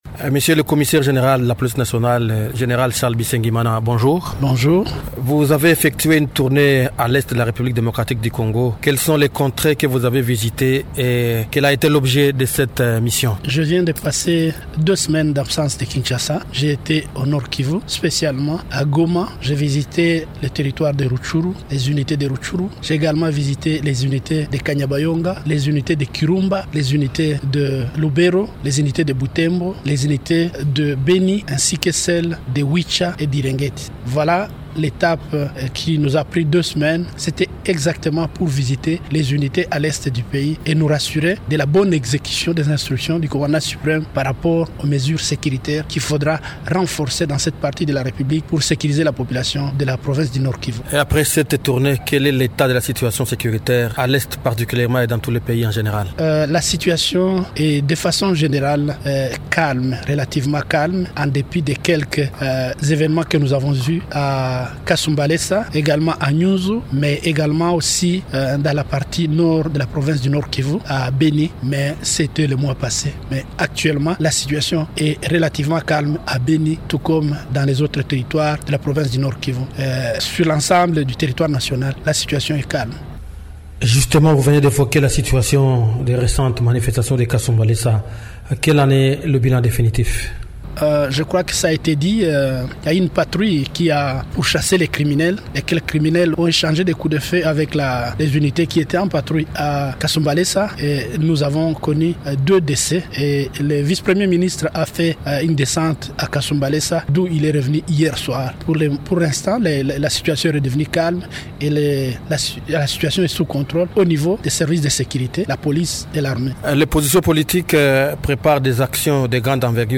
Invité de Radio Okapi ce mardi, il indique avoir effectué cette visite pour inspecter les unités de police de ces territoires en proie à la violence et s’assurer de la mise en œuvre effective des instructions sécuritaires données par le président de la République.